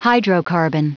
Prononciation du mot hydrocarbon en anglais (fichier audio)
Prononciation du mot : hydrocarbon